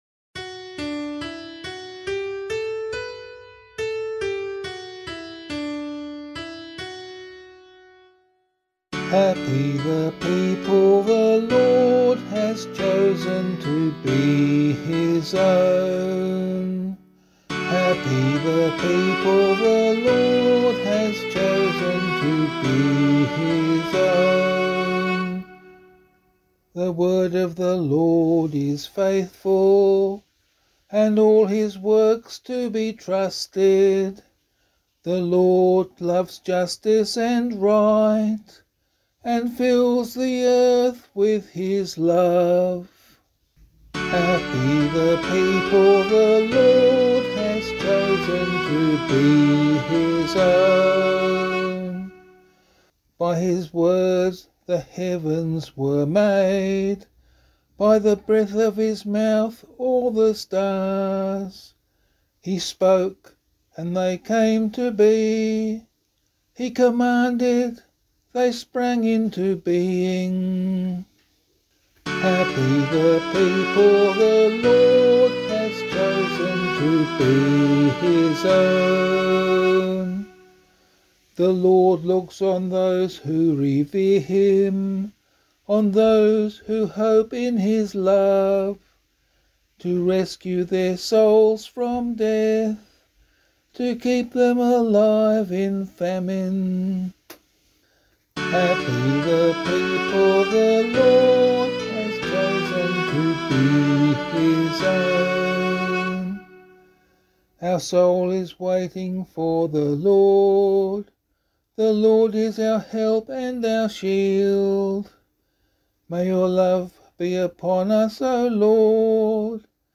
033 Trinity Psalm B [LiturgyShare 5 - Oz] - vocal.mp3